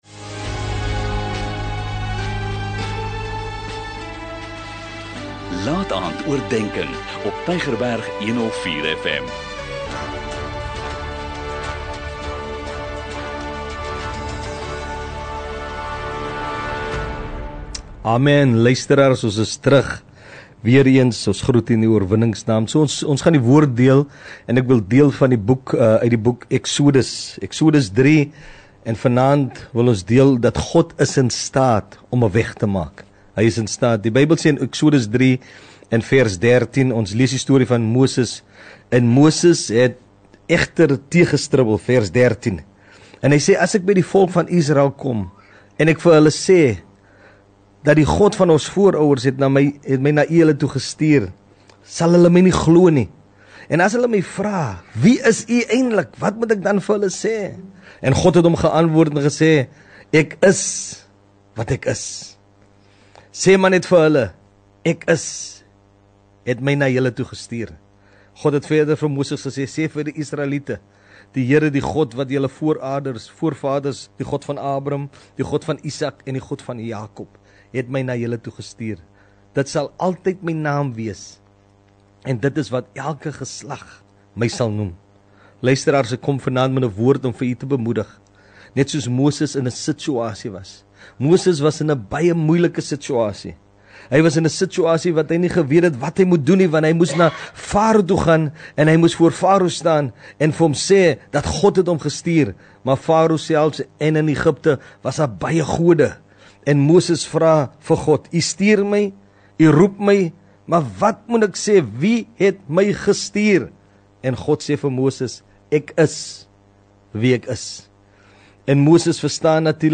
'n Kort bemoedigende boodskap, elke Sondagaand om 20:45, aangebied deur verskeie predikers.
Sondag-laataandoordenking 2 Mar 02 MRT 2025